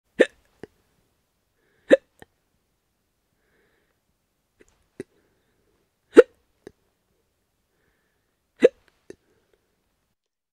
دانلود آهنگ سکسکه 2 از افکت صوتی انسان و موجودات زنده
دانلود صدای سکسکه 2 از ساعد نیوز با لینک مستقیم و کیفیت بالا
جلوه های صوتی